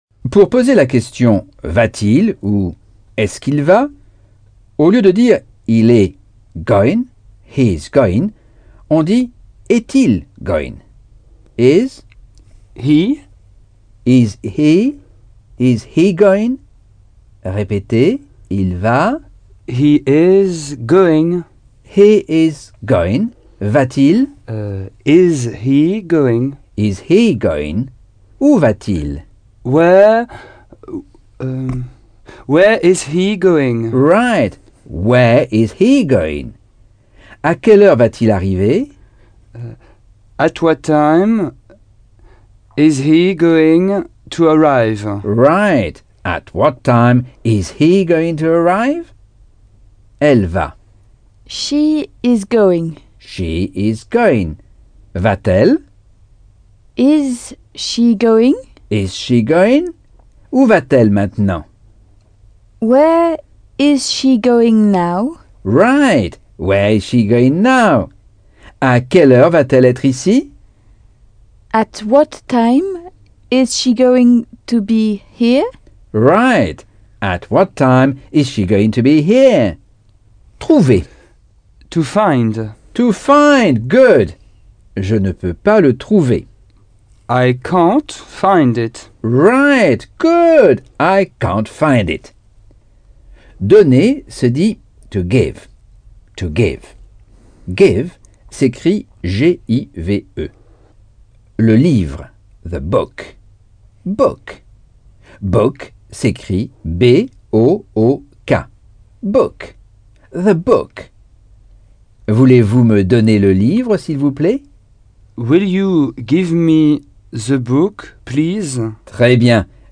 Leçon 8 - Cours audio Anglais par Michel Thomas